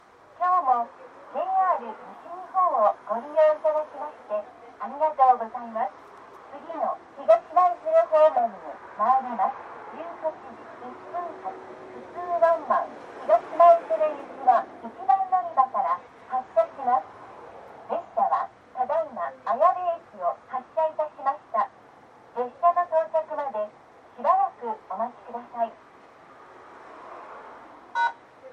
この駅では接近放送・予告放送が導入されています。
予告放送普通ワンマン　東舞鶴行き予告放送です。